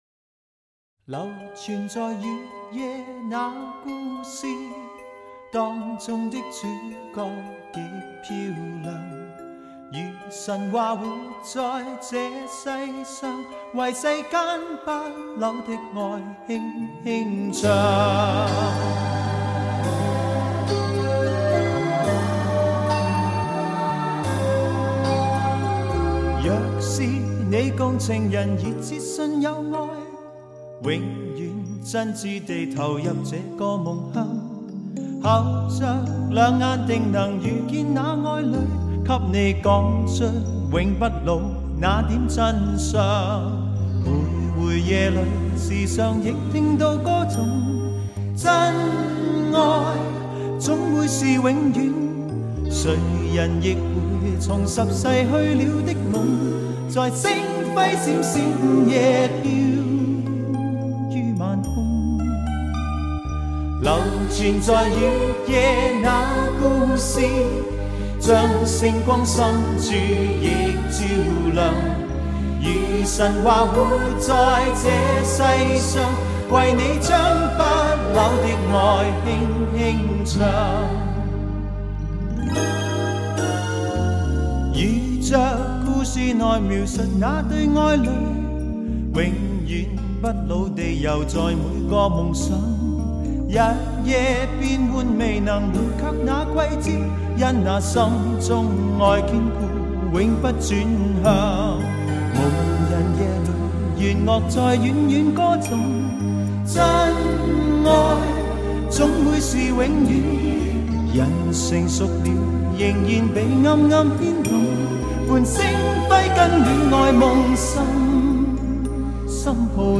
音色更接近模拟(Analogue)声效
强劲动态音效中横溢出细致韵味